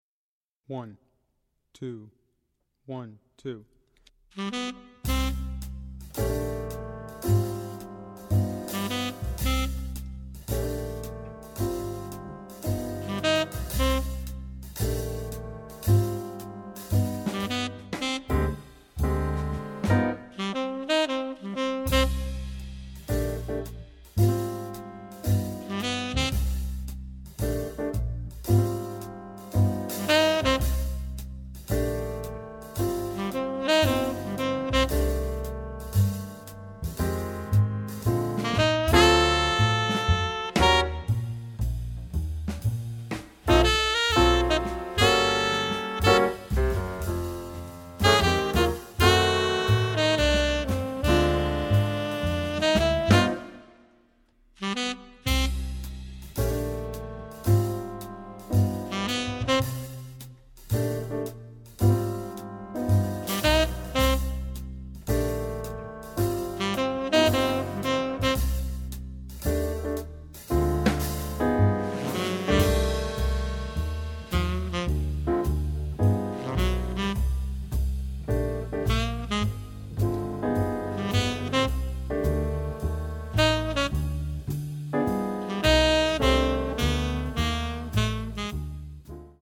Voicing: String Bass Collection